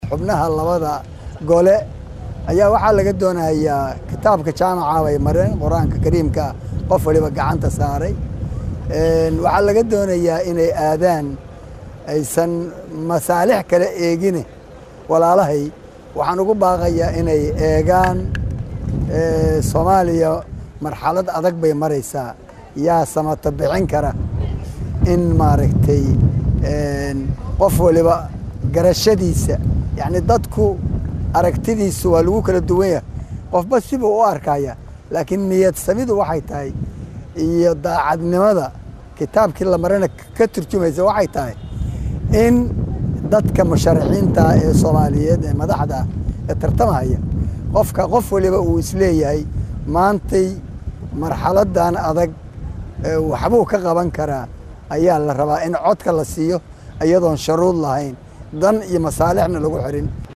Dhegeyso: Xildhibaan Cabdi Qeybdiid oo Baaq U Diraaya Xildhibaanada Cusub ee Dowlada Federaalka